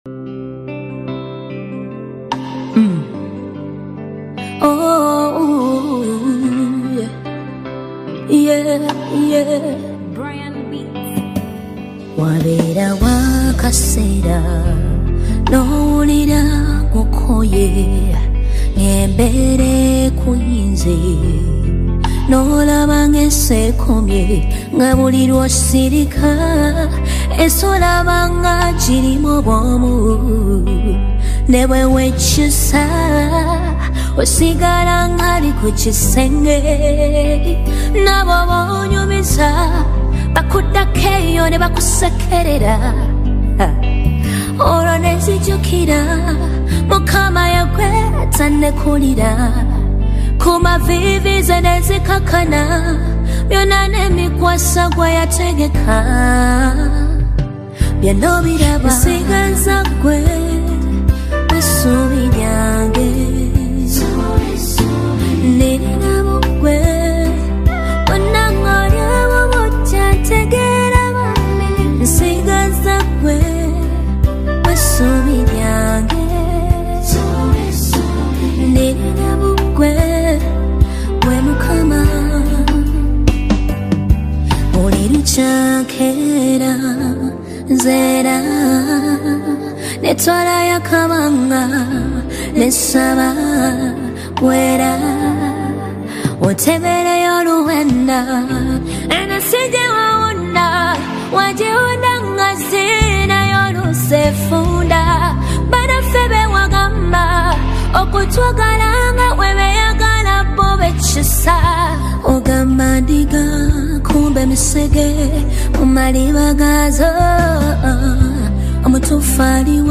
gospel song
With her clear voice and gentle delivery